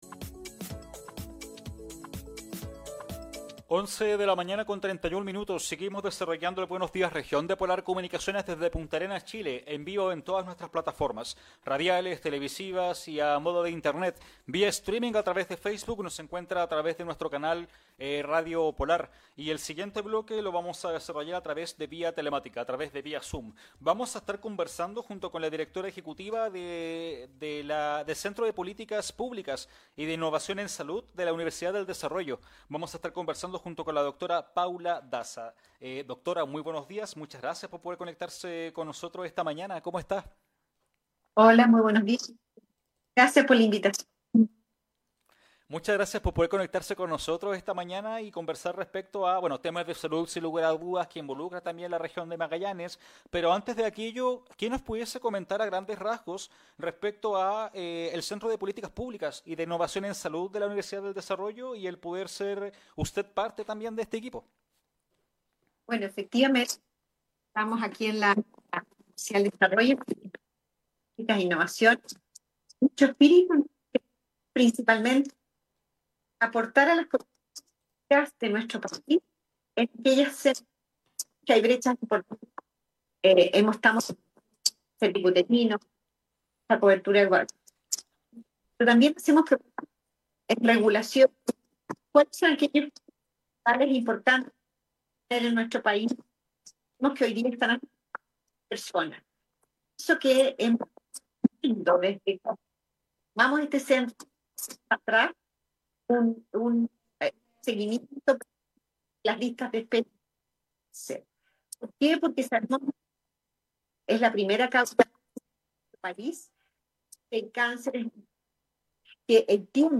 La directora ejecutiva de CIPS UDD, Paula Daza, comenta informe sobre las listas de espera en la salud pública y datos en retrasos GES a lo largo del país. Además, se refiere a la campaña de vacunación contra virus respiratorios.